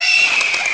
下載音效
怪物聲音(1)怪物聲音(2)